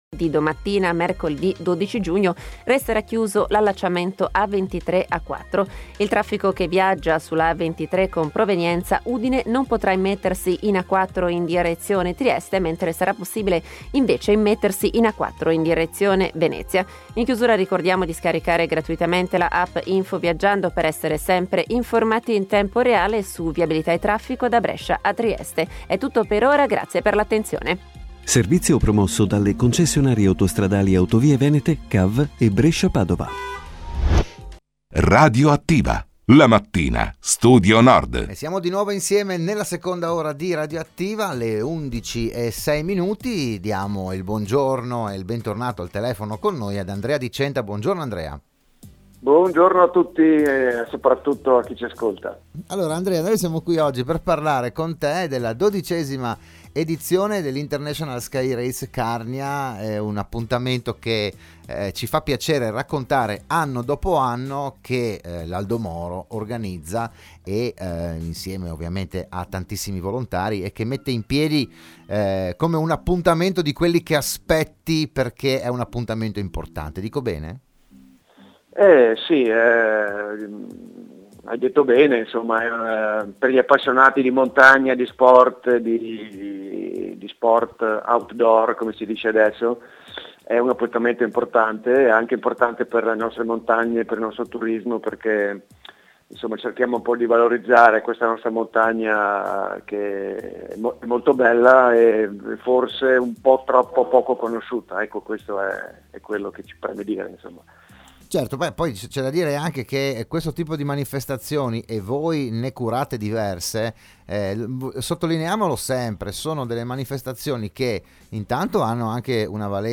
Il PODCAST dell'intervento a Radio Studio Nord